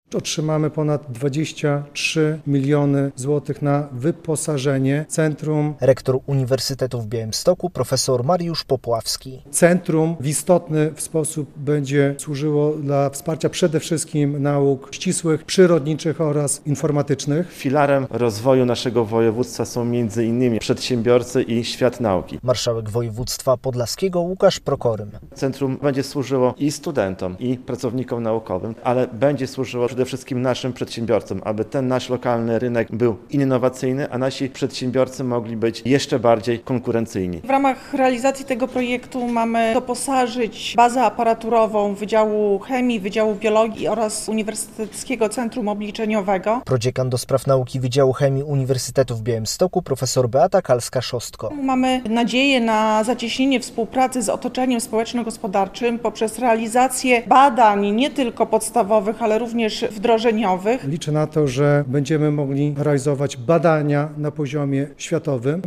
Powstanie BioNanoTechno na UwB - relacja